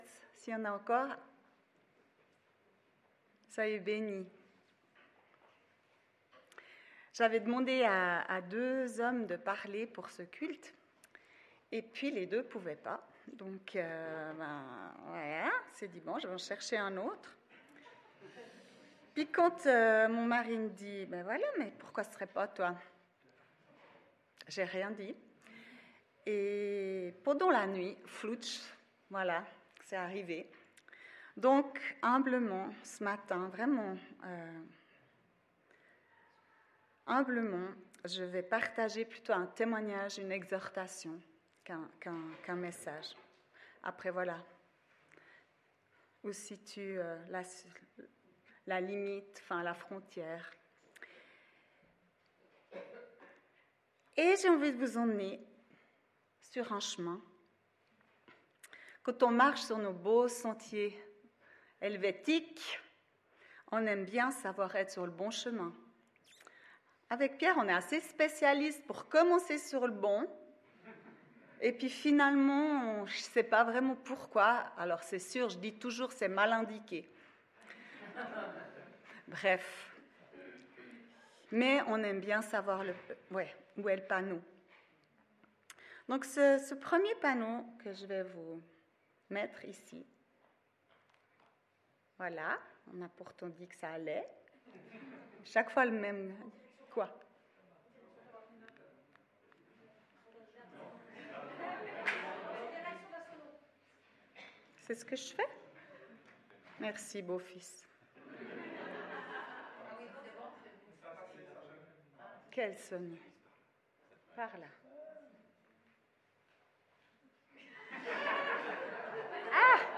Témoignage: Reconstruction, réconciliation et pardon